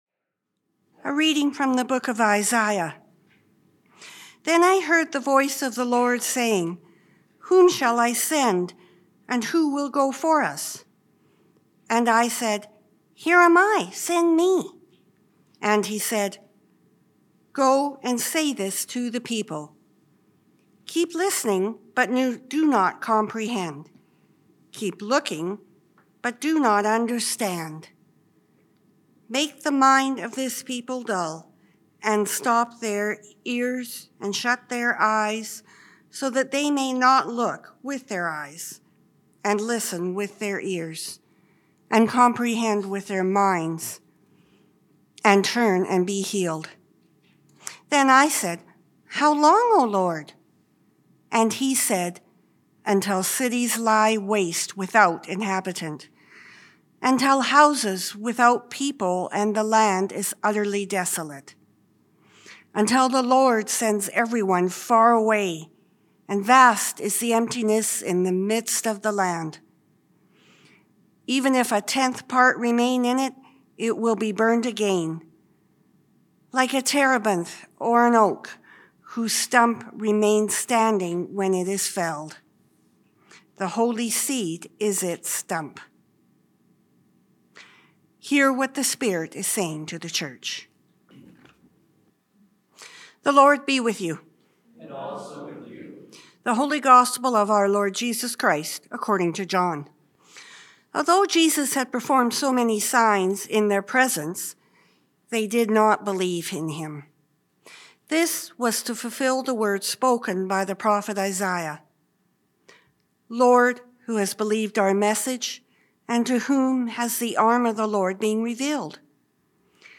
NOTE: The camera was not set up to record on this date, so only the audio of the readings and the sermon are provided.